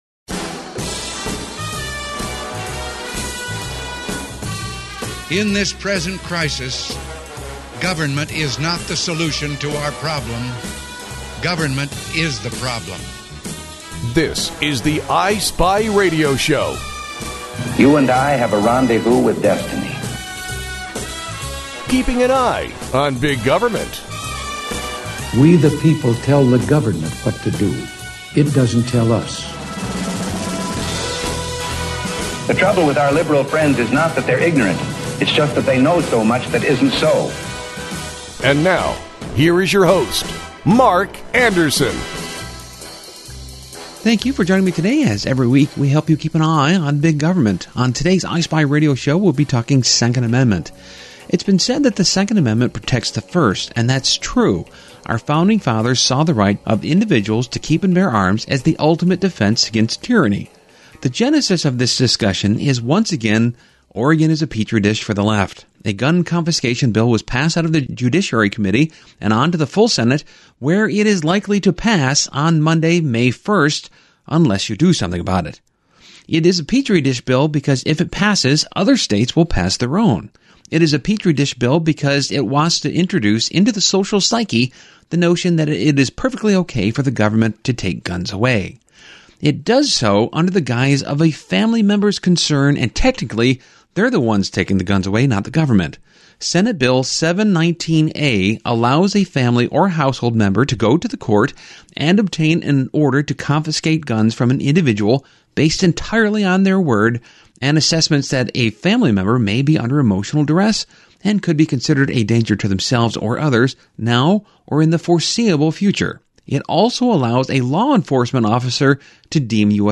Show 13-41 Summary: Get some great insight into the Israel-Hamas war and into Israel’s surrounding neighbors. We’re talking with best-selling author, Scott McEwen, co-author of American Sniper, who has a ton of military contacts and special ops insiders he relies on to write his books. We talk about what happened, why it happened, and address some conspiracy theories.